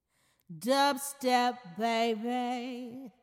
标签： 96 bpm Dubstep Loops Vocal Loops 556.30 KB wav Key : Unknown
声道立体声